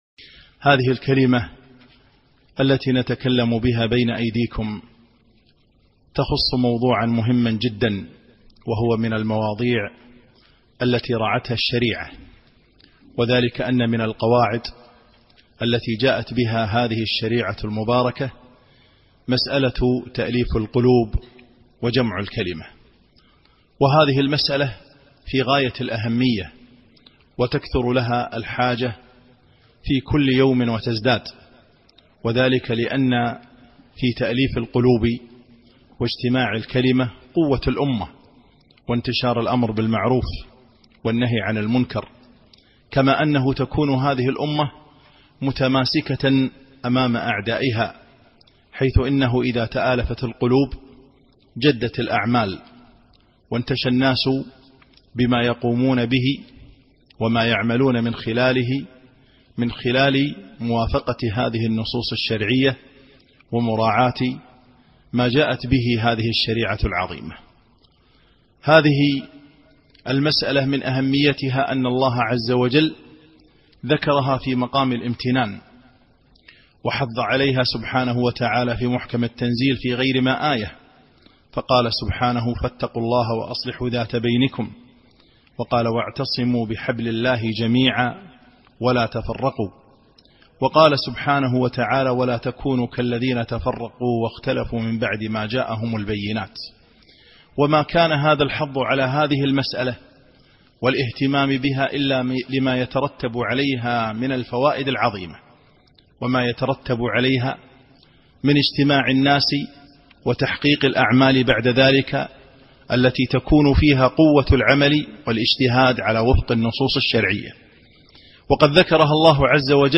خطبة -تاليف القلوب و جمع الكلمة